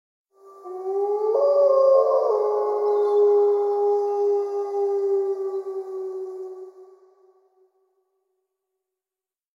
• Качество: 128, Stereo
страшные
звуки волка